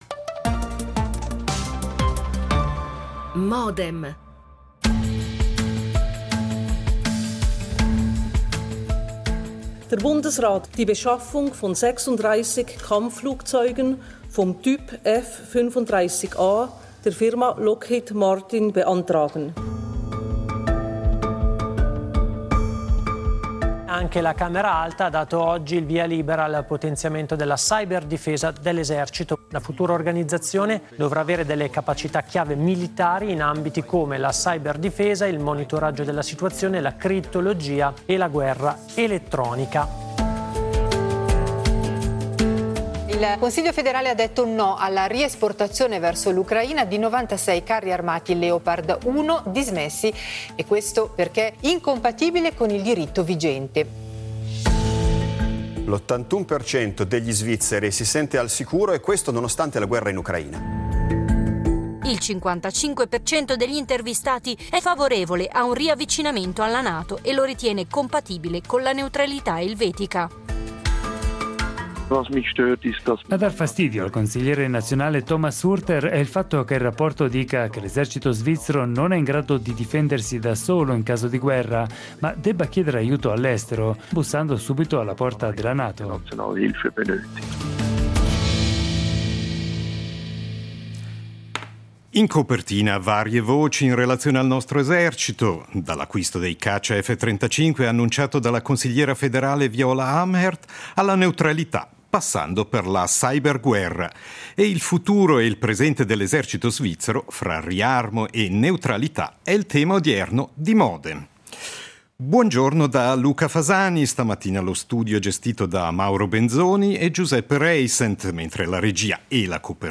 Avanti Marsch! Intervista con il capo dell’esercito